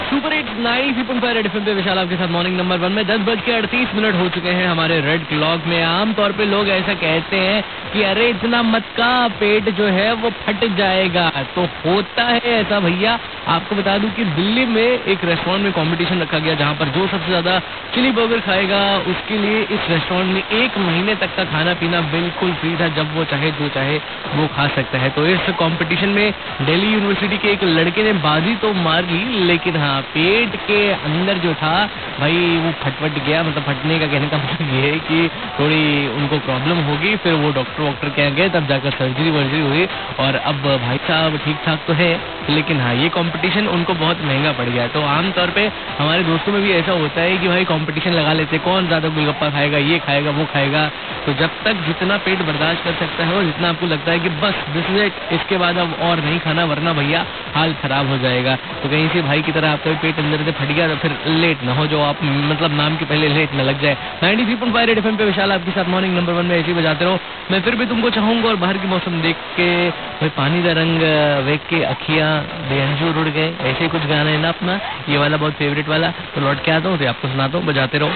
RJ TALKING ABOUT BURGER COMPITITION